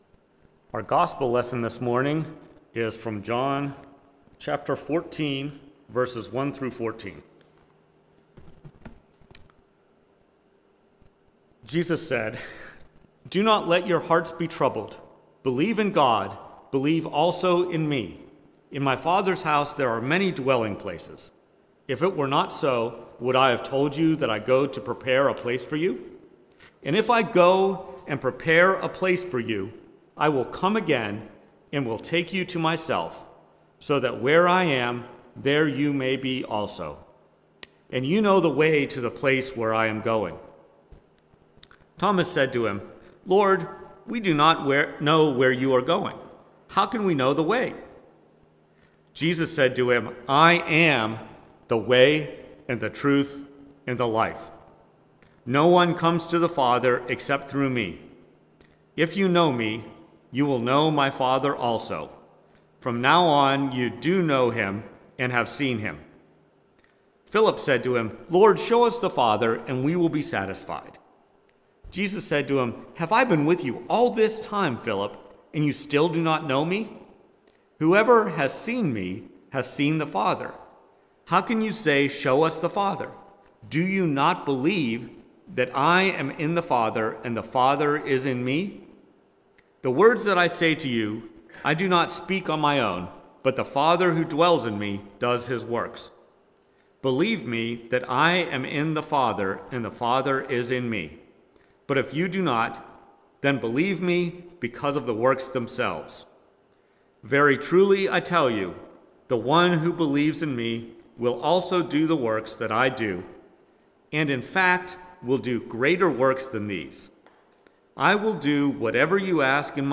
Preached at First Presbyterian Church of Rolla on May 7, 2023. Based on John 14:1-14.